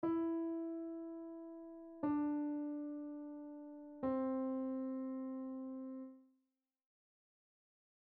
Piano Notes
edc.mp3